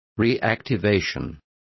Complete with pronunciation of the translation of reactivation.